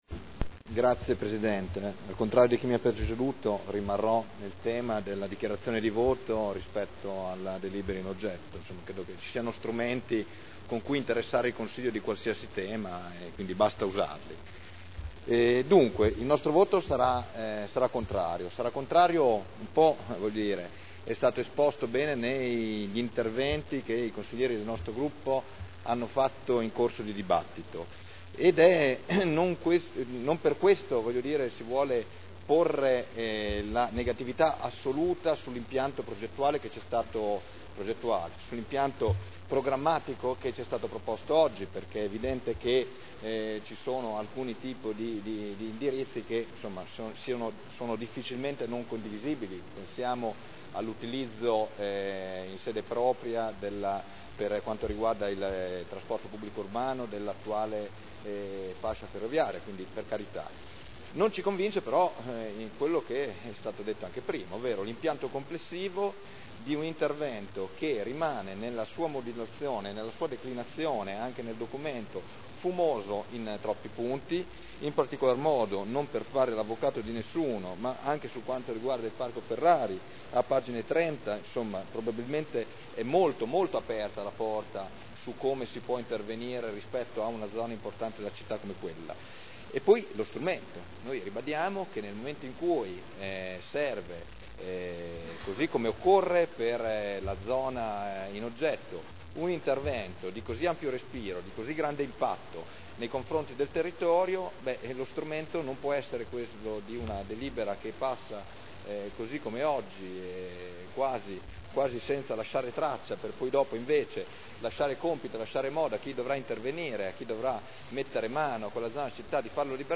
Michele Barcaiuolo — Sito Audio Consiglio Comunale
Seduta del 01/02/2010. La Città della riqualificazione – Programma di riqualificazione urbana per il quadrante urbano di Modena Ovest – Approvazione del documento di indirizzo dichiarazioni di voto